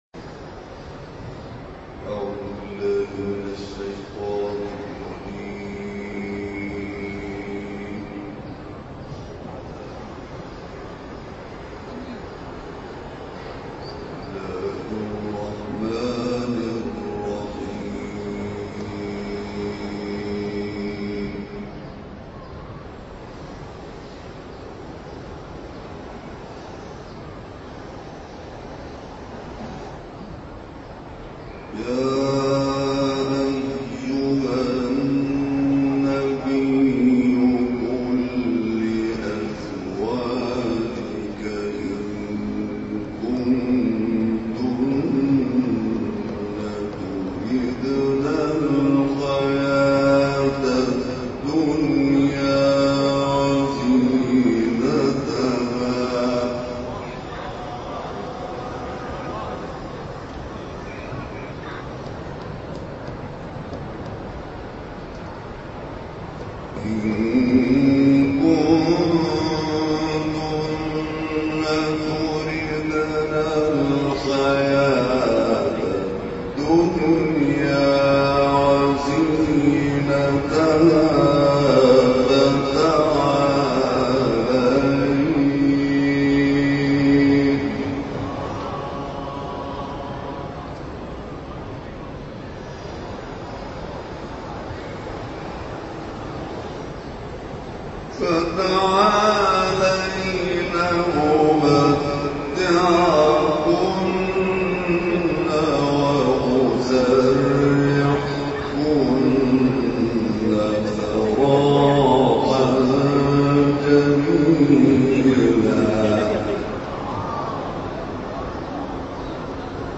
این تلاوت در ماه مبارک رمضان در یک محفل انس با قرآن در حرم مطهر امام حسین(ع) اجرا شده است.